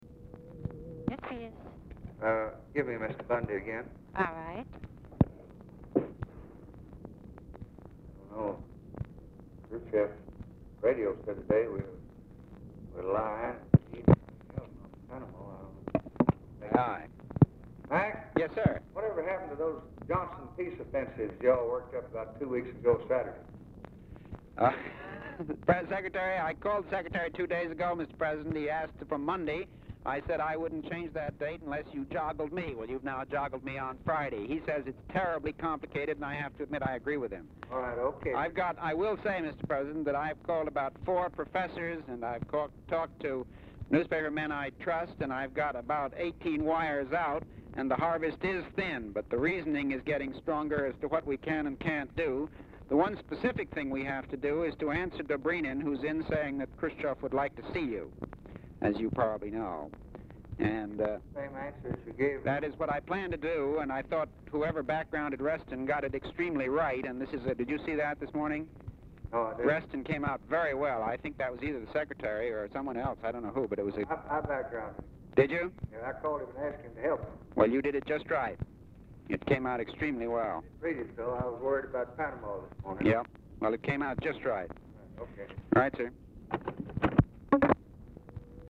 Oval Office or unknown location
"TRANS"; OFFICE CONVERSATION PRECEDES CALL
Telephone conversation
Dictation belt